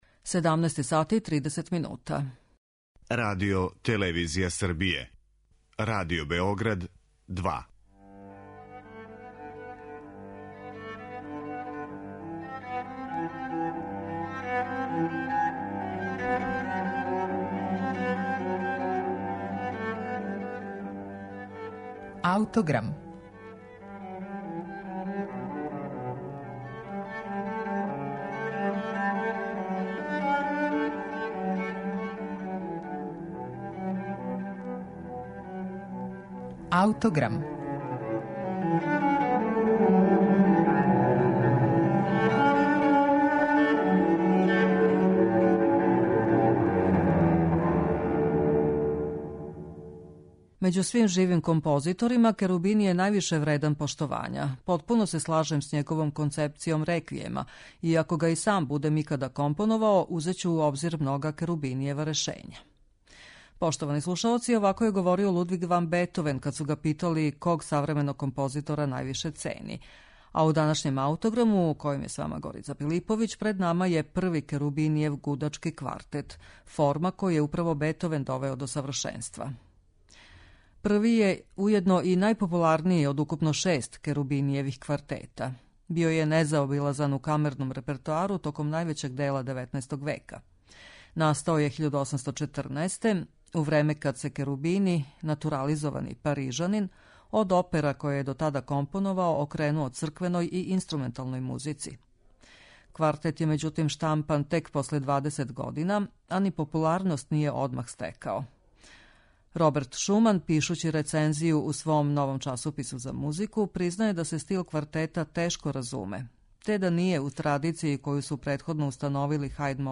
Први гудачки квартет Луиђија Керубинија